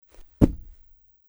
跺脚－YS070525.mp3
通用动作/01人物/01移动状态/跺脚－YS070525.mp3
• 声道 立體聲 (2ch)